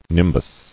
[nim·bus]